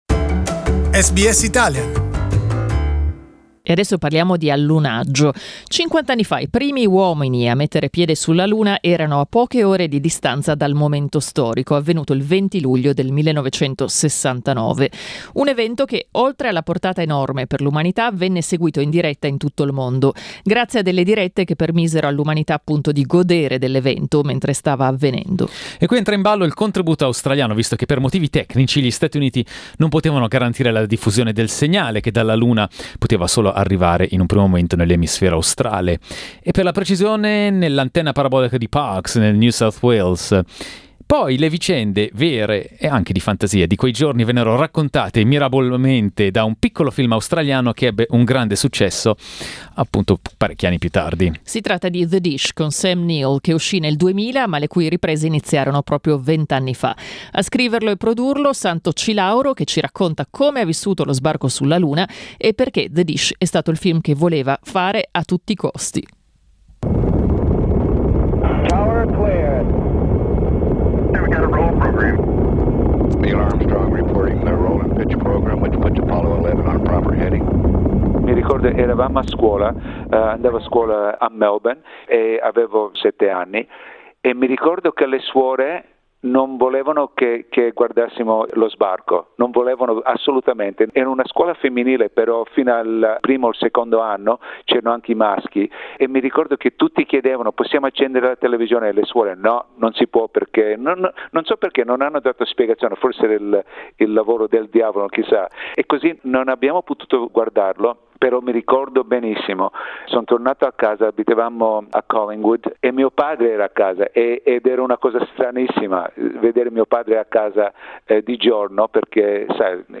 Santo Cilauro, one of the writers and producers of the movie The Dish, tells SBS Italian what it took to produce the film, almost 20 years ago.